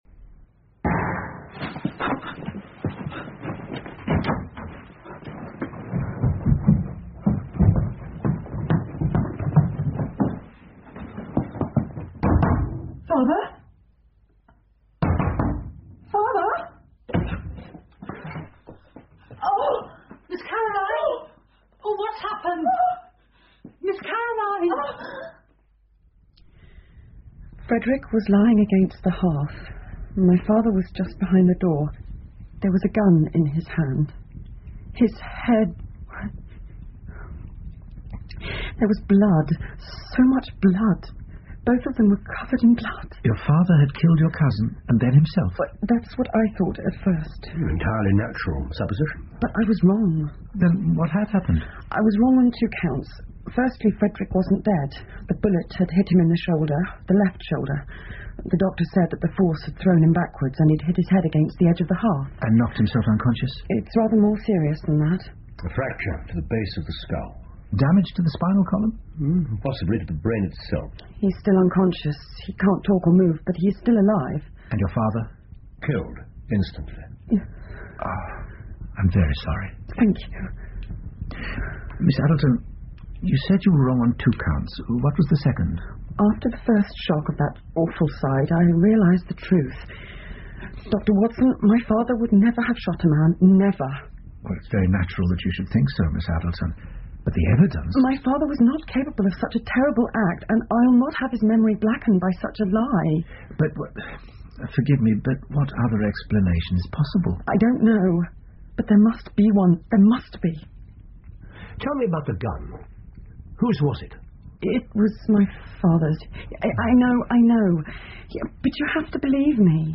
福尔摩斯广播剧 The Determined Client 3 听力文件下载—在线英语听力室